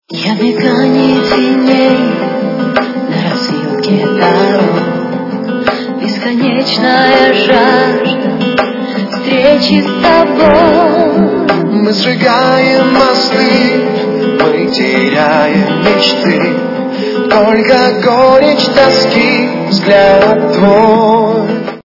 русская эстрада
При заказе вы получаете реалтон без искажений.